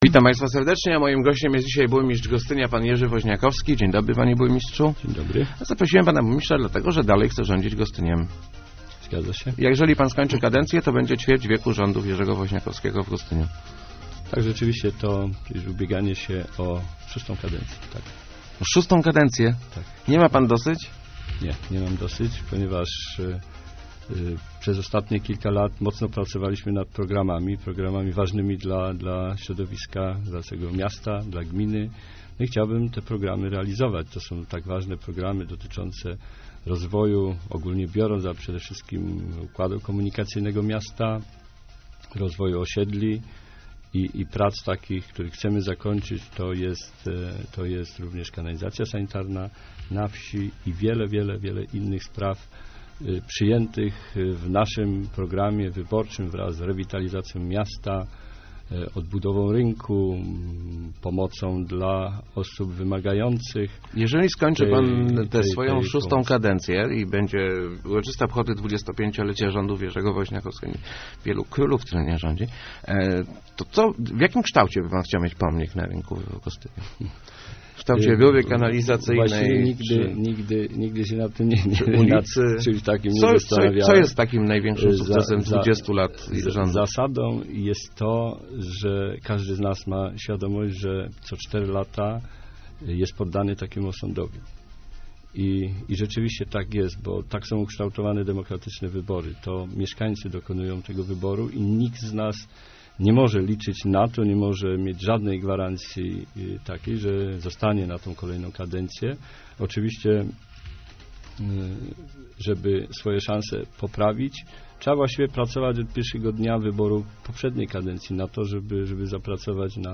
Droga 434 przez Gostyń będzie realizowana w latach 2011-2012 - zapewniał w Rozmowach Elki burmistrz Gostynia Jerzy Woźniakowski.